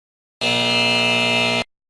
Descargar-Efecto-de-Sonido-Falla-Error-en-HD-Sin-Copyright-.mp3